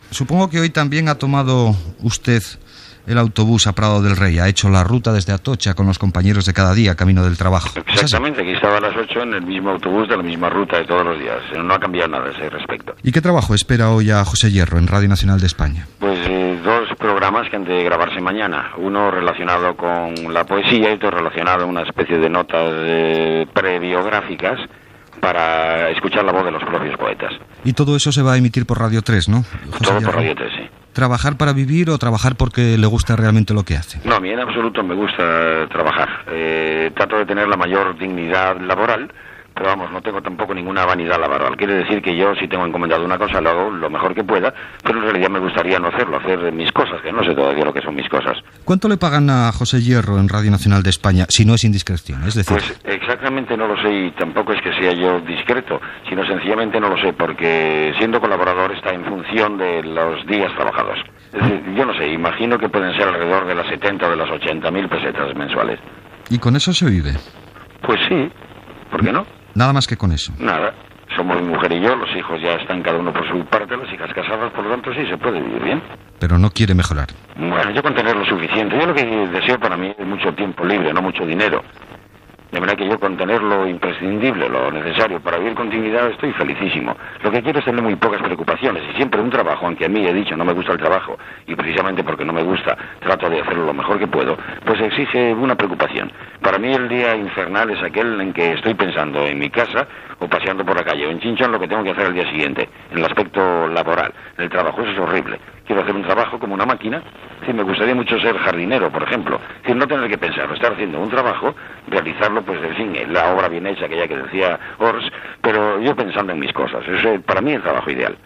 Entrevista al poeta José Hierro sobre la seva feina de col·laborador a Radio Nacional de España.
Entreteniment